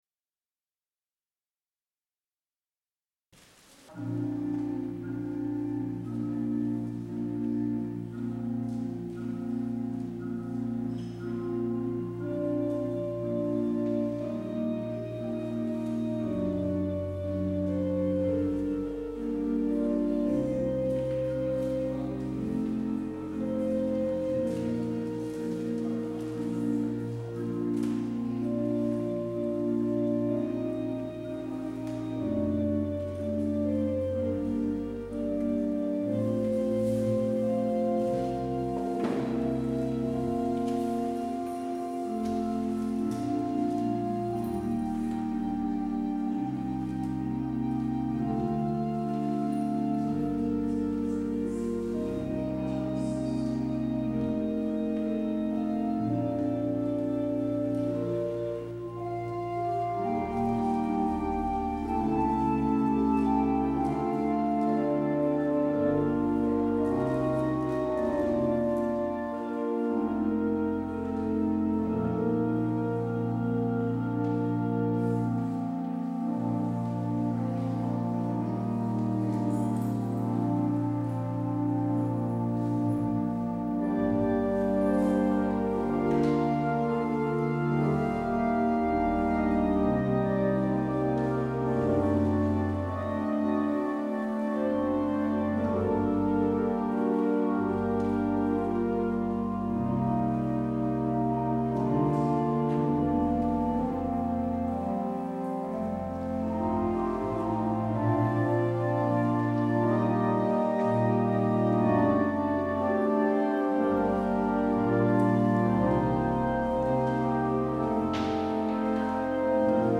 Luister deze kerkdienst terug